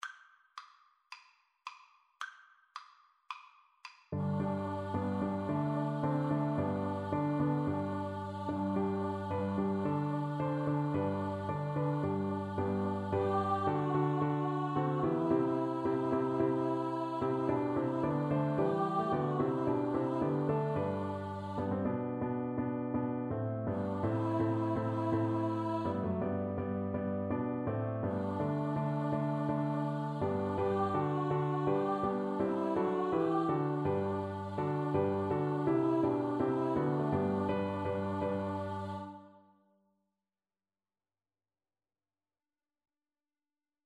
Free Sheet music for Choir (SATB)
Allegro moderato = c. 110 (View more music marked Allegro)
4/4 (View more 4/4 Music)
Classical (View more Classical Choir Music)